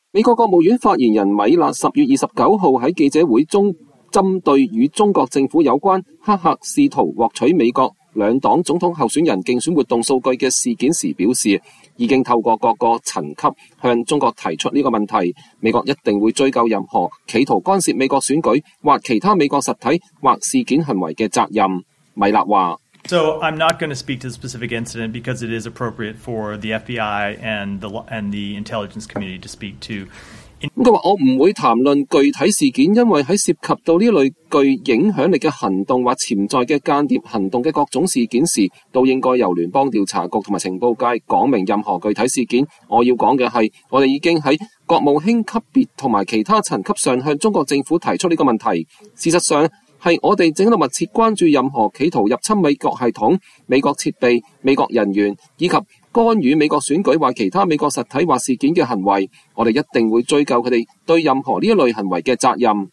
美國國務院發言人米勒10月29日在記者會中，針對與中國政府有關黑客試圖獲取美國兩黨總統候選人競選活動數據的事件時表示，已經透過各層級向中國提出這一問題，美方一定會追究任何企圖干涉美國選舉或其他美國實體或事件行為的責任。